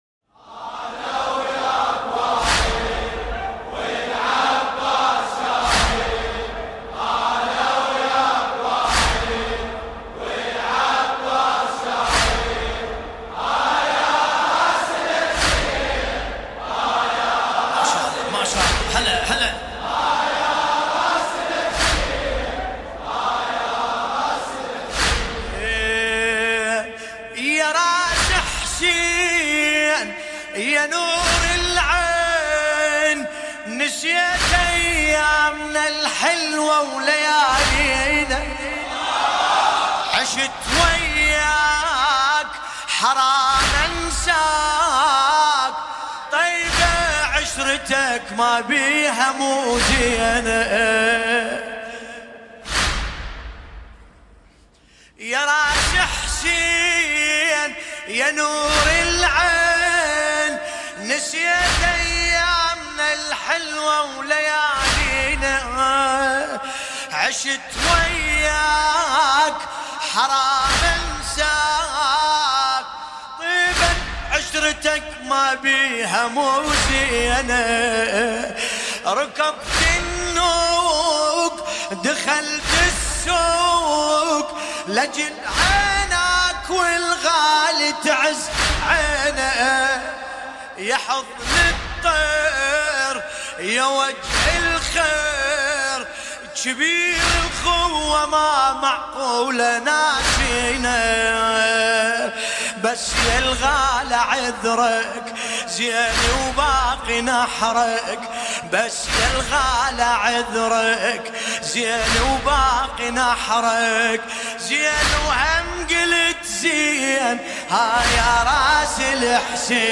قصيدة